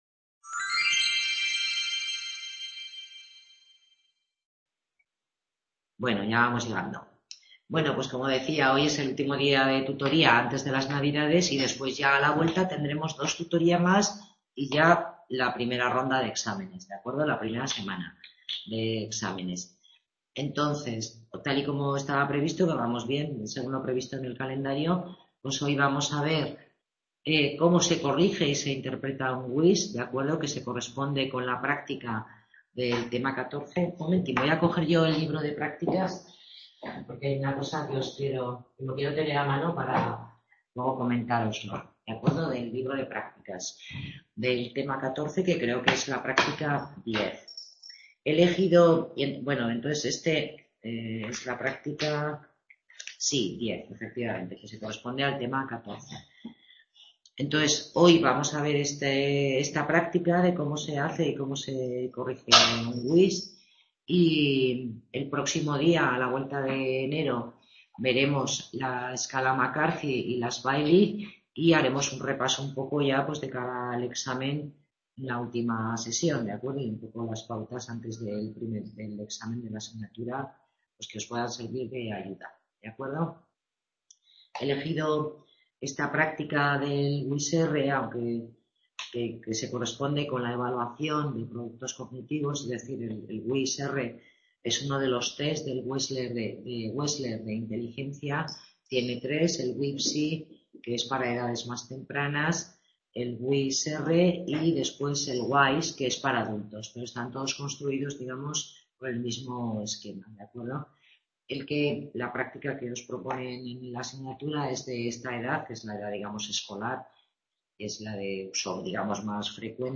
Tutoría grupal dedicada a la práctica sobre la corrección e interpretación del test WISC-R, de inteligencia para niños